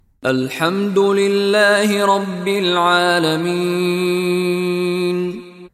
Contoh Bacaan dari Sheikh Mishary Rashid Al-Afasy
Bunyi Huruf Mim disebut dengan JELAS (dengan merapatkan bibir) tanpa dengung.